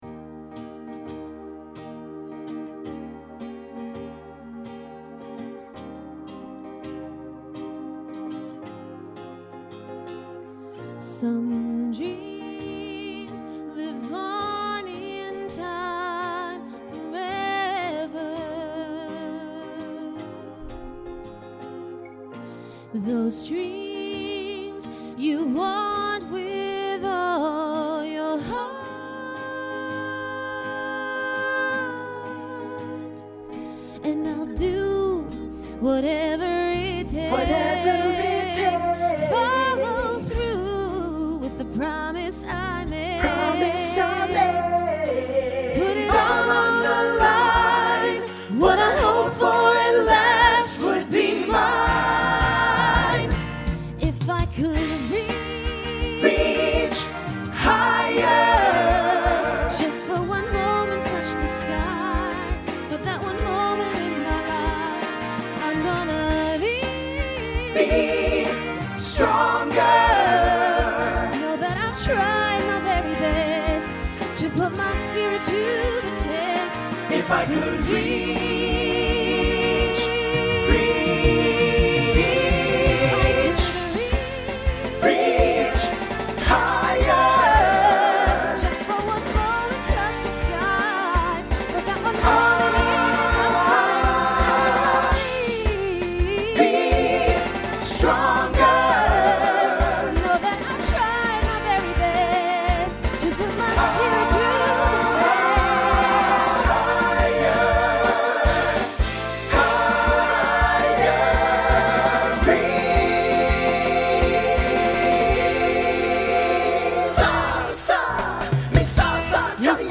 Voicing Mixed
Genre Latin , Pop/Dance